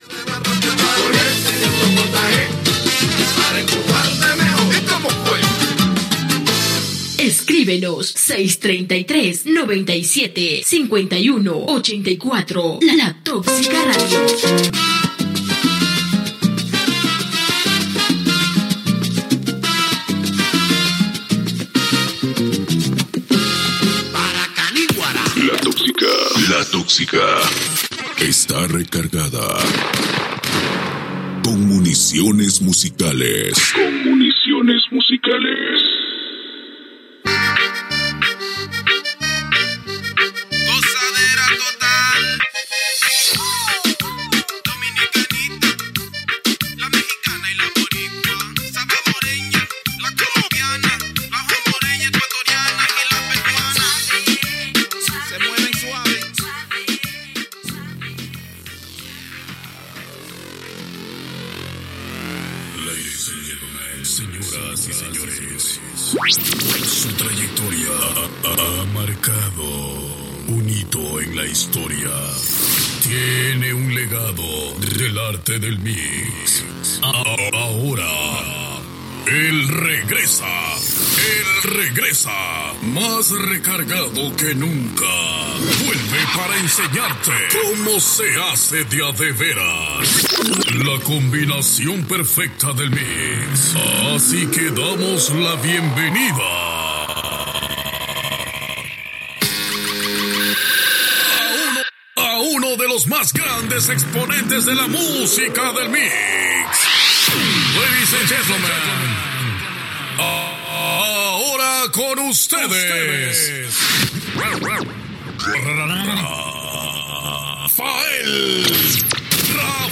Temes musicals i indicatius